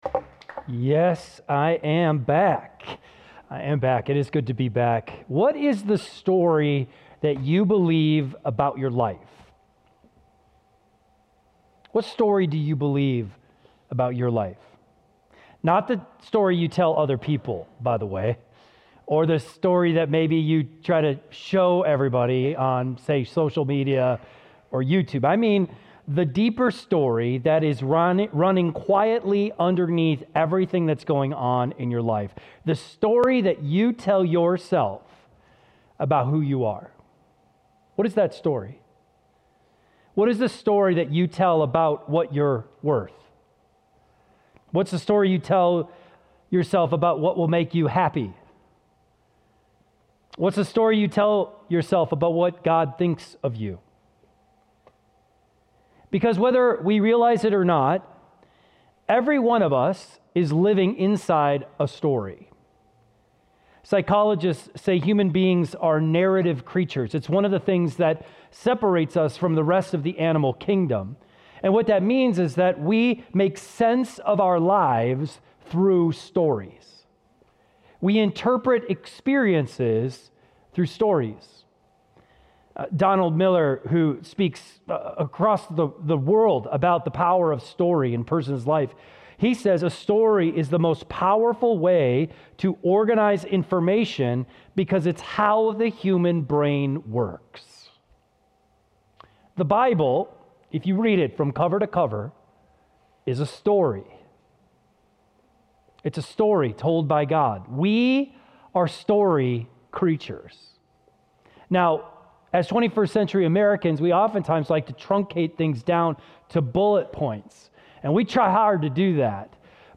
keyboard_arrow_left Sermons / Give It Up Series Download MP3